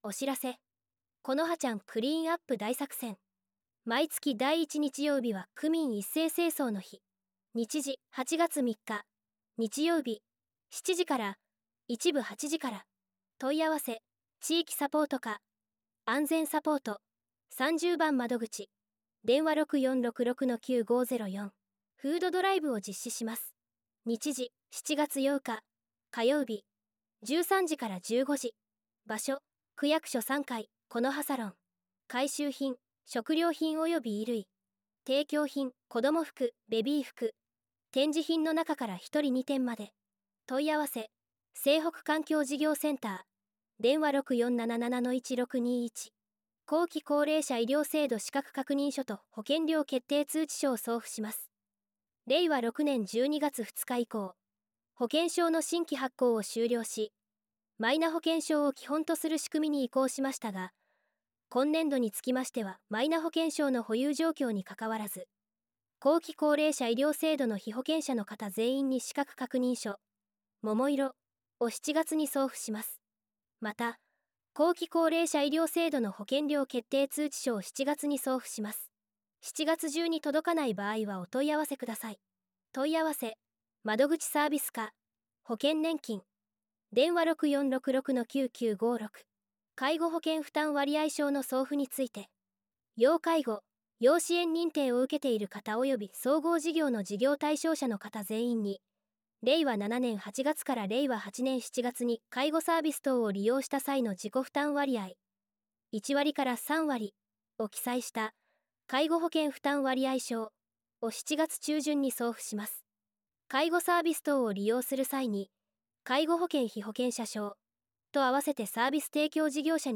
音声版　広報「このはな」令和7年7月号